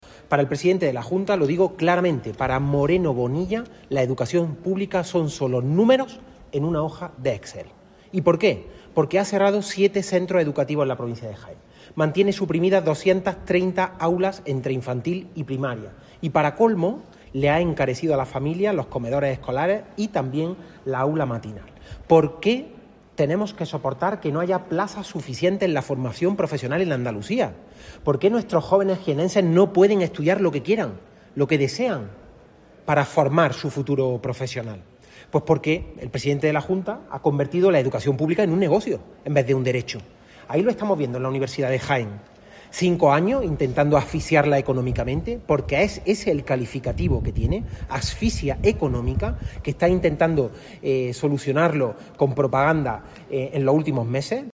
De la Rosa, que ha participado en un Foro sobre Educación organizado por el PSOE de Jaén en la ciudad de Úbeda, manifestó que la educación construye sociedad y que hay dos modelos: una educación “para unir” y otra “para segregar”.